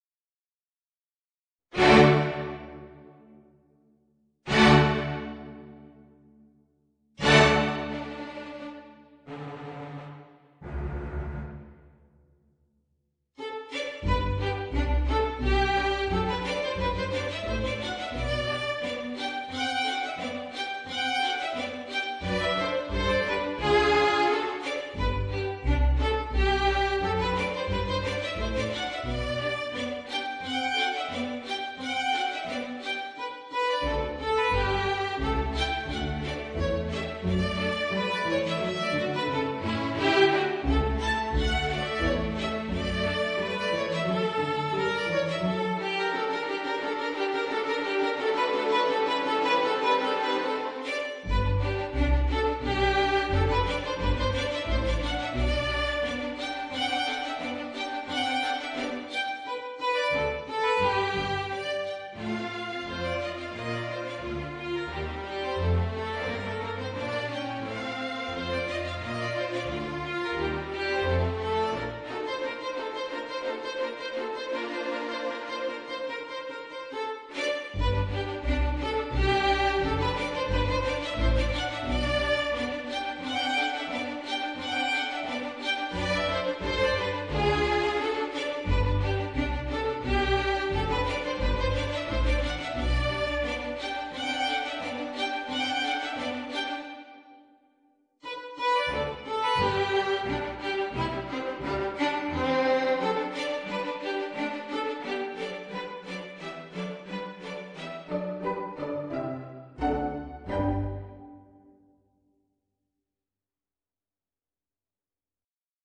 Voicing: Oboe and String Orchestra